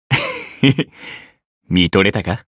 觉醒语音 えっへへ…見とれたか？